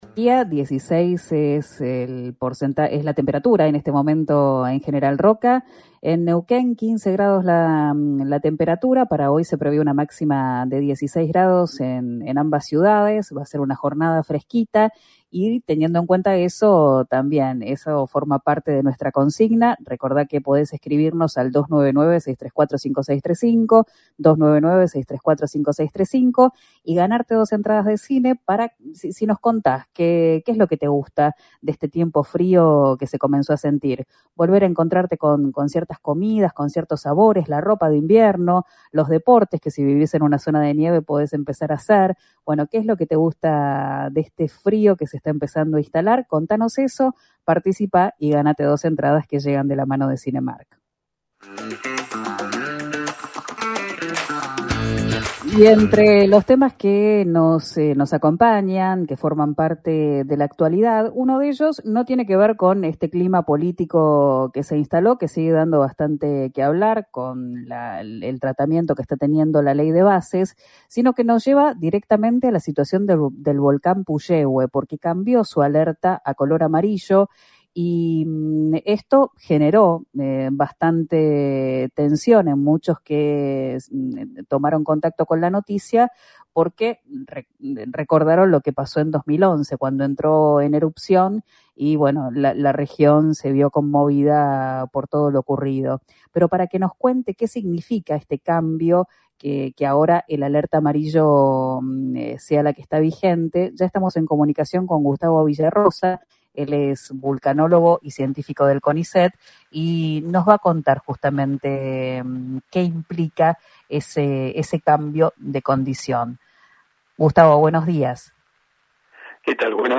Fue durante una entrevista en Río Negro Radio, a la que fue convocado para hablar del complejo volcánico Cordón Caulle Puyehue.